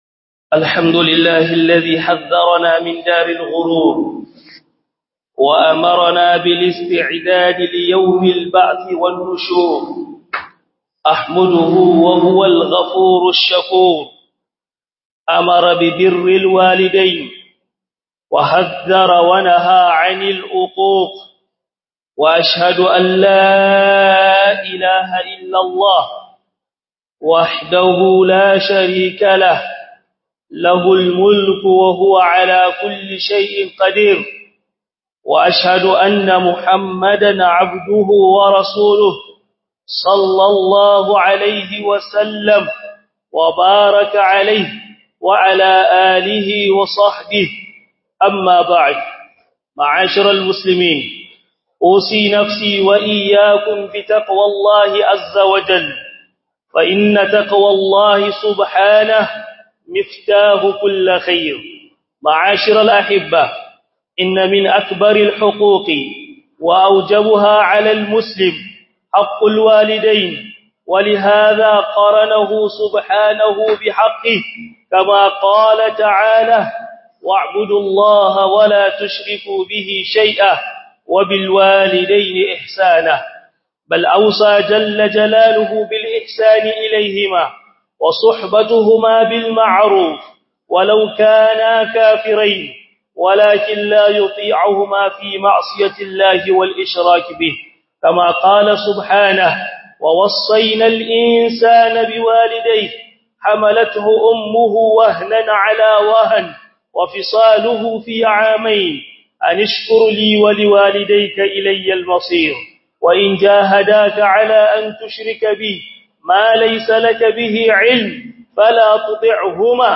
Khudubar Juma'ah 26 Rabi'ul Thaani 1445AH